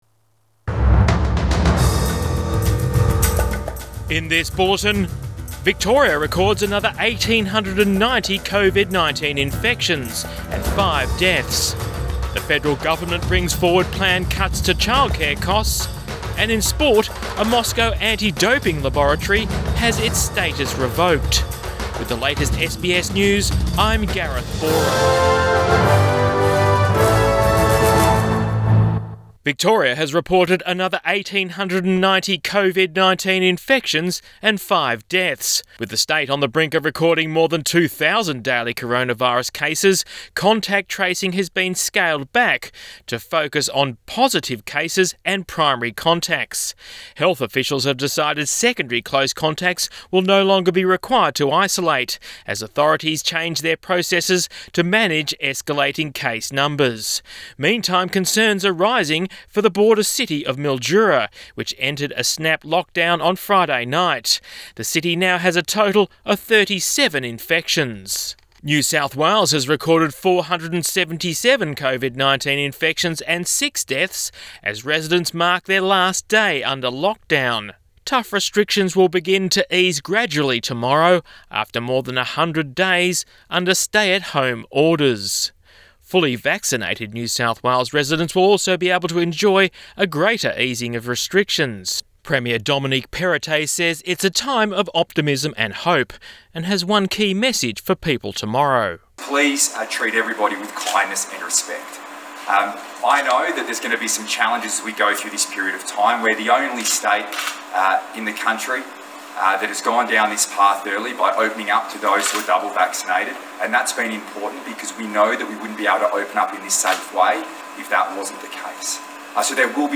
Midday bulletin 10 October 2021